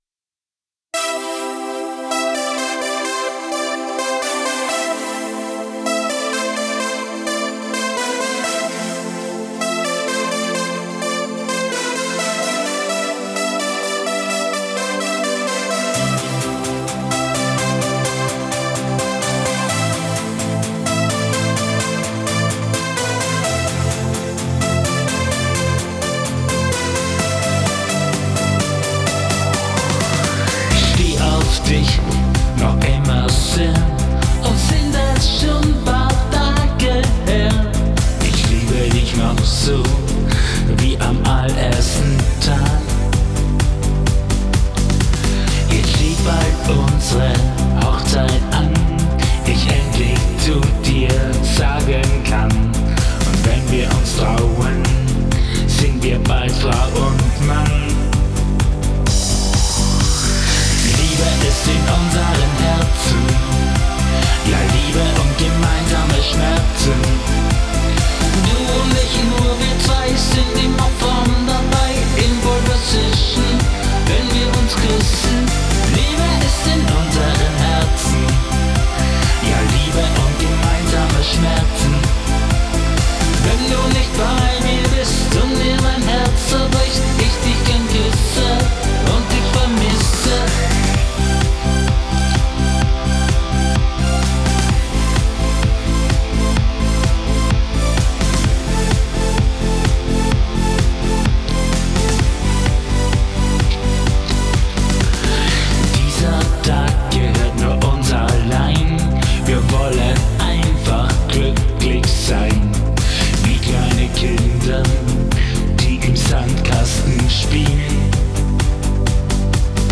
[Pop-Schlager] Liebe ist in unseren Herzen